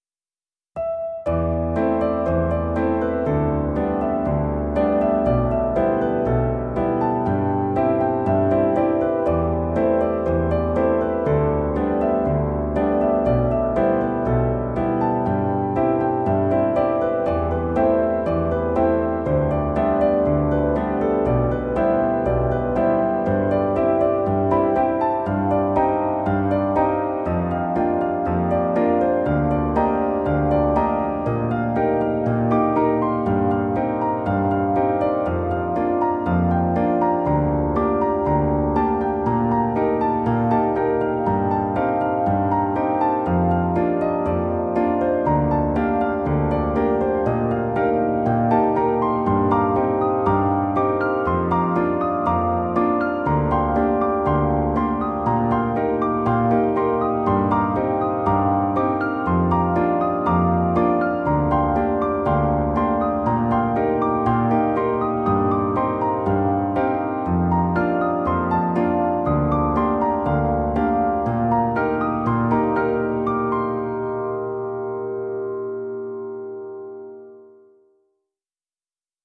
PIANO T-Z (21)